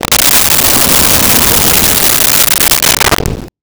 Wind Howl 04
Wind Howl 04.wav